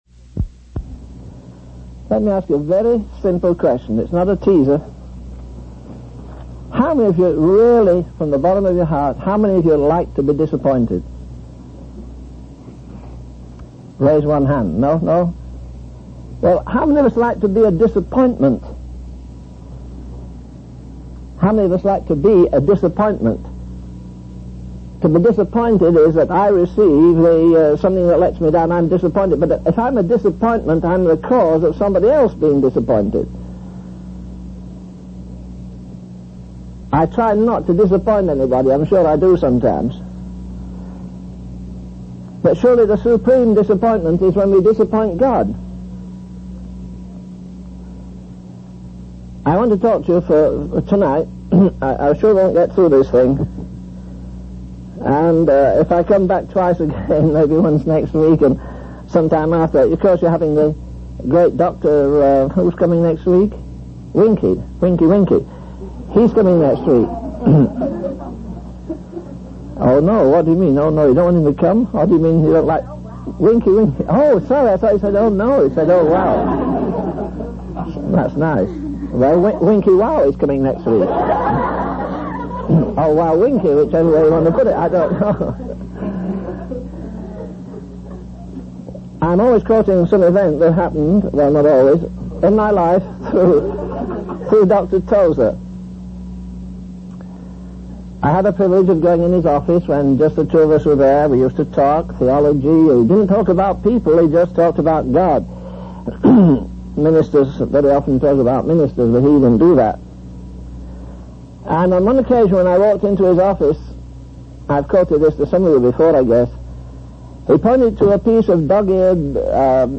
In this sermon, the speaker begins by dividing the sermon into three parts, with the promise of a more engaging sermon in the following weeks. He then reads from Exodus 24, emphasizing the essence of worship and the experience of Moses coming near to the Lord.